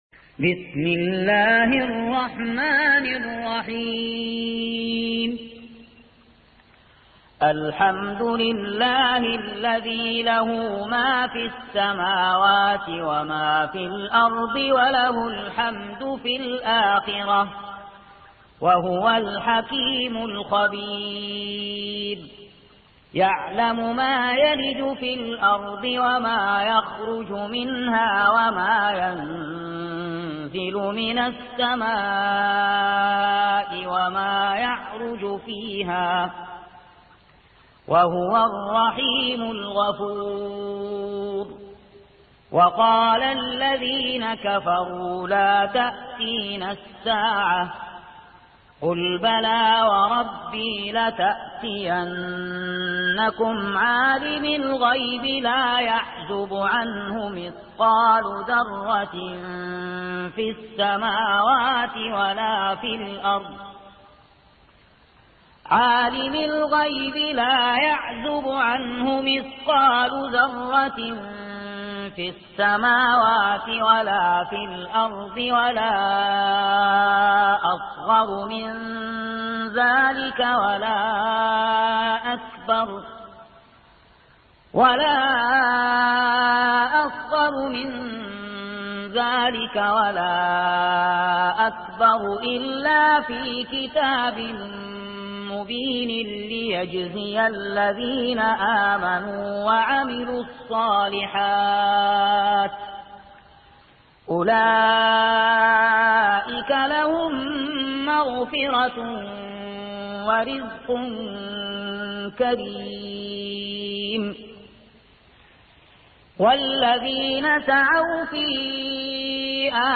سورة سبأ | القارئ